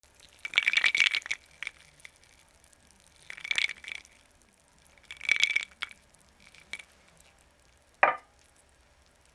氷の入ったグラスをゆらす